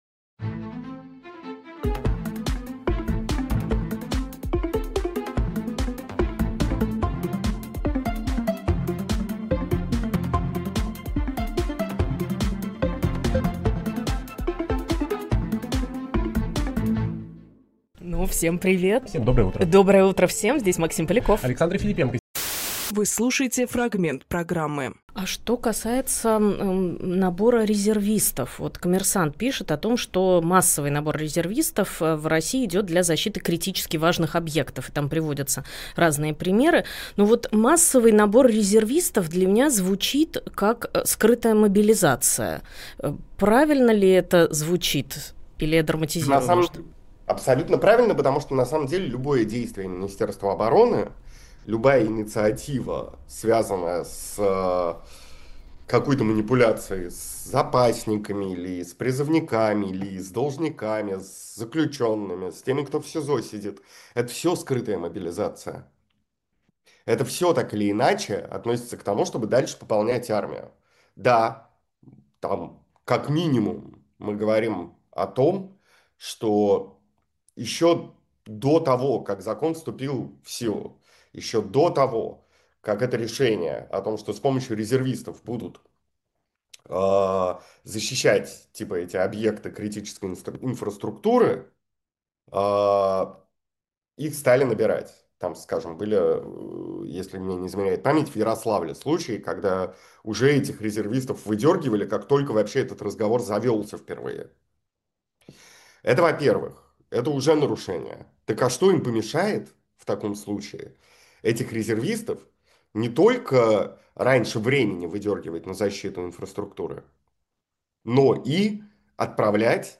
Фрагмент эфира от 10.11.25